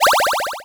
buttonfx.wav